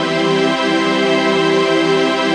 DM PAD2-60.wav